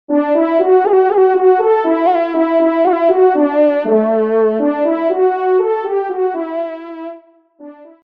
FANFARE
Extrait de l’audio « Ton de Vènerie »